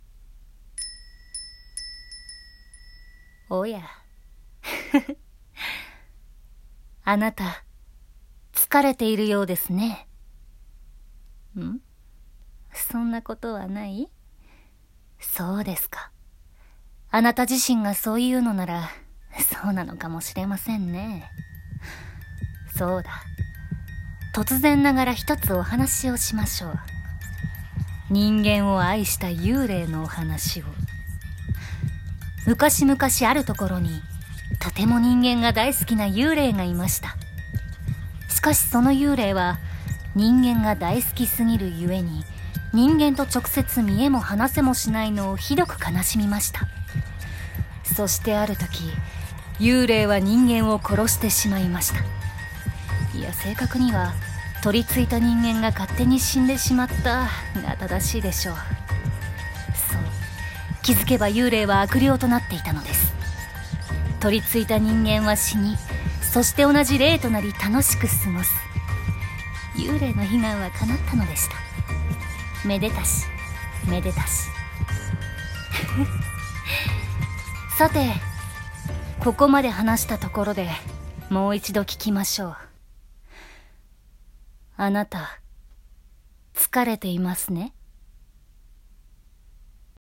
【声劇】あなた、つかれていますね？【ホラー】